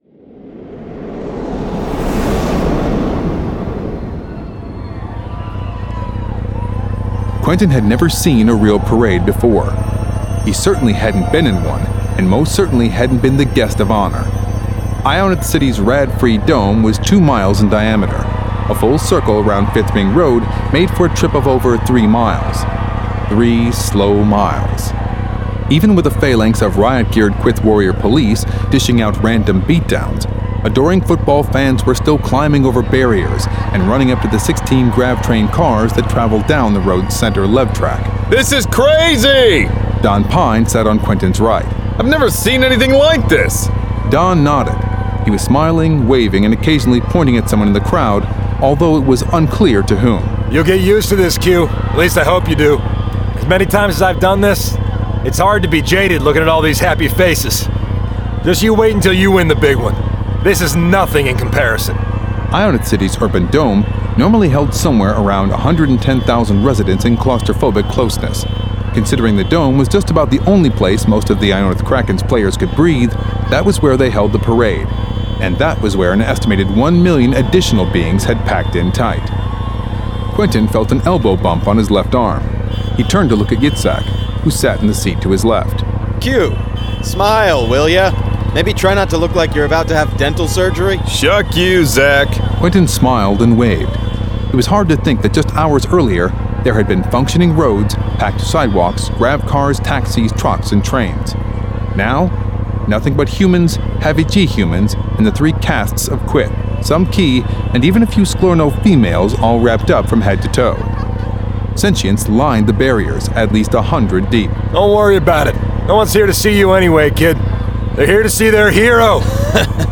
Galactic Football League 2: The Starter 1 of 2 [Dramatized Adaptation]